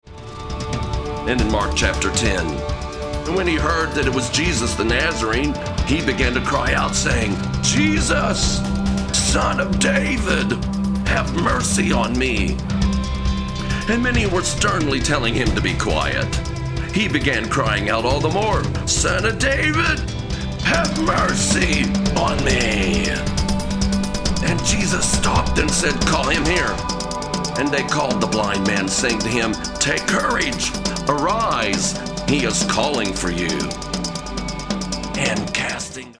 Background rhythms with flowing melodies